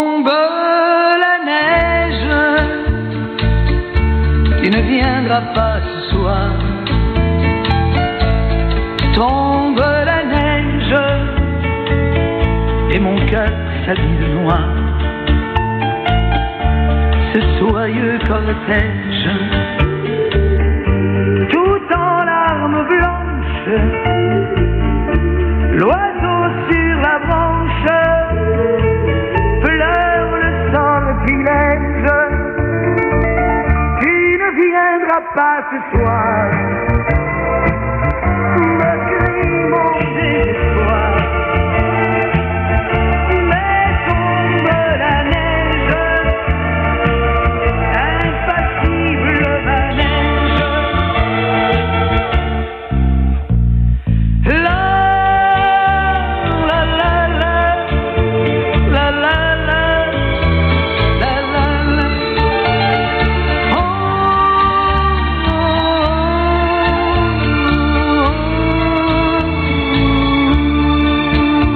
受信音を録音してみました。
③　送信モード＝LSB、帯域＝4.0KHｚ
LSB帯域テスト録音
LSB＝モノラル音、ISB=ステレオ音となります。